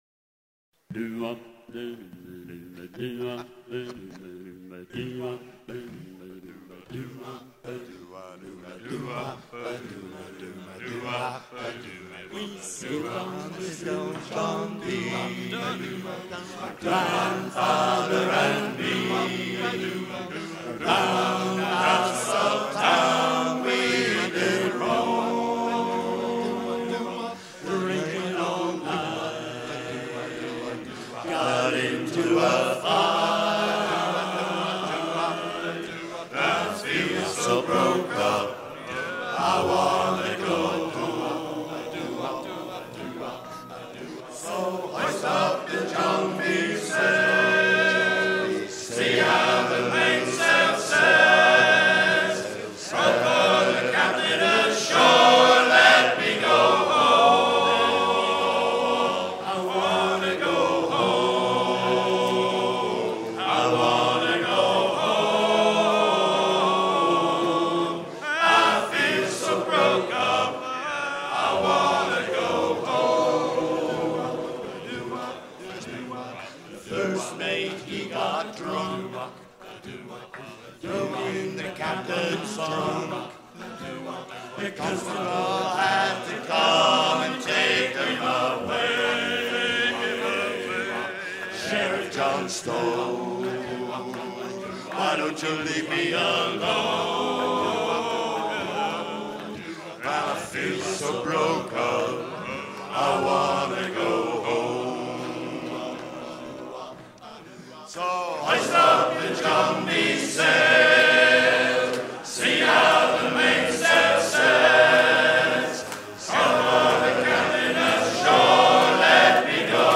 chant des Caraïbes
Pièce musicale éditée